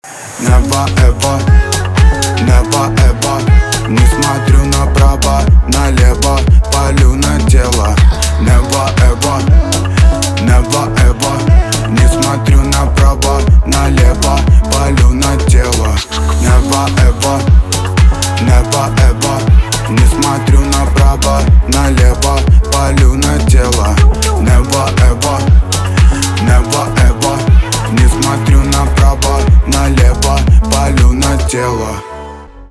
русский рэп , битовые , басы